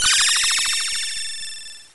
Retro Game Weapons Sound Effects – Starpower 003 – Free Music Download For Creators
Retro Game Weapons Sound Effects Sounds effects types: pistol load, pistol shoot, shotgun shoot, shotgun reload, 50 caliber mg shoot, ak47 sound effects, ar15 sound effects, assault rifle, bullet hit, gun
Retro_Game_Weapons_Sound_Effects_-_Starpower__003.mp3